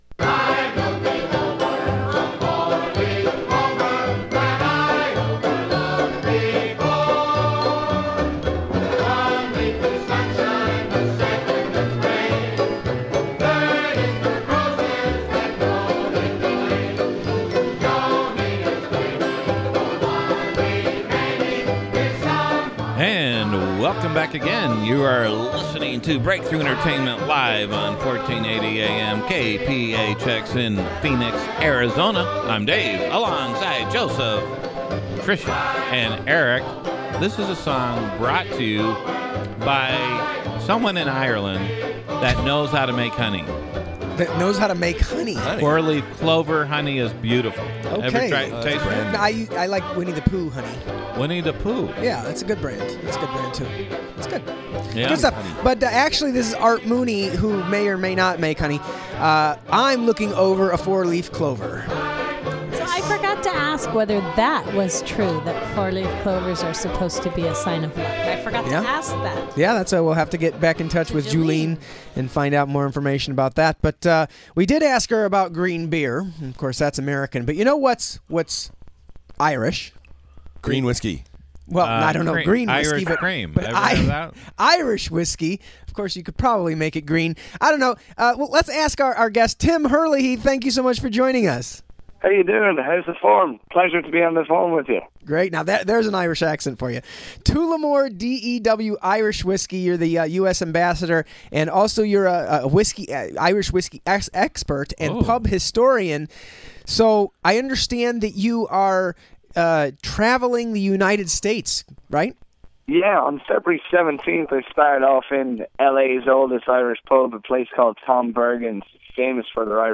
a talk radio show